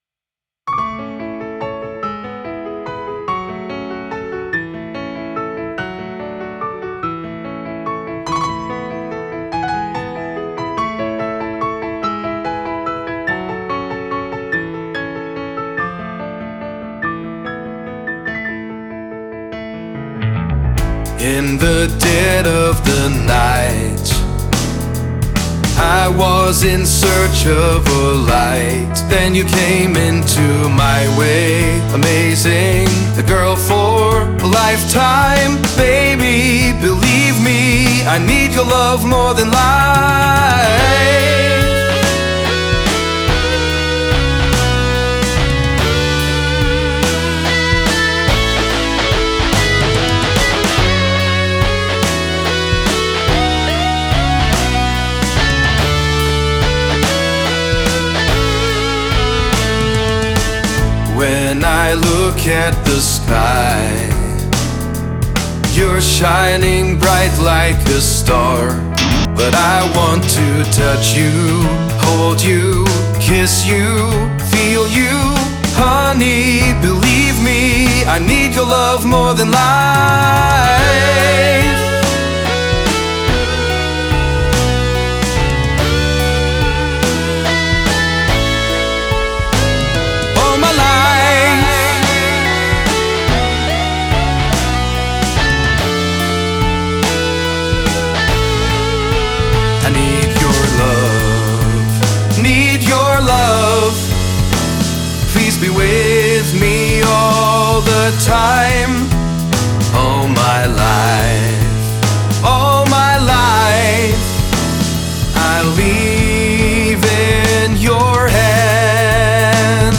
Prayer fue la primera canción que grabé con voz simulada.
La versión con mejor mezcla y más detalles vocales es esta: